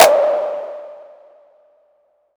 • Long Room Reverb Clap Single Hit D Key 26.wav
Royality free hand clap - kick tuned to the D note. Loudest frequency: 1991Hz
long-room-reverb-clap-single-hit-d-key-26-kWf.wav